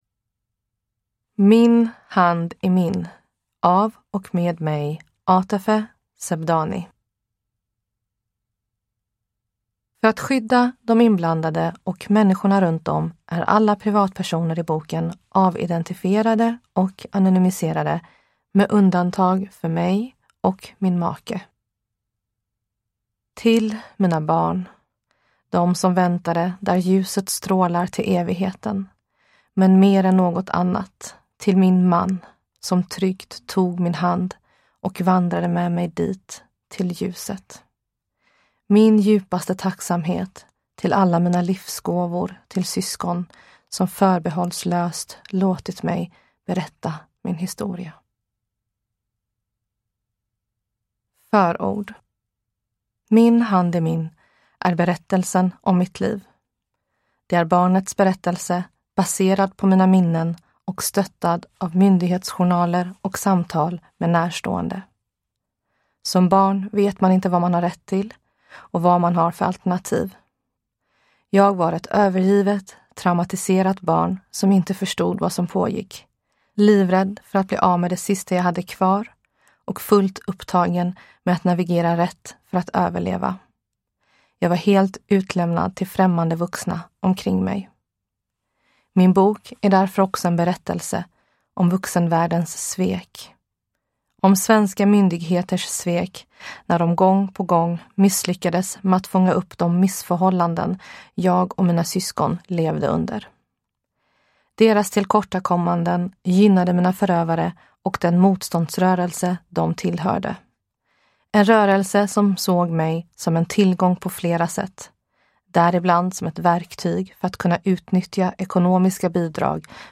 Min hand i min – Ljudbok – Laddas ner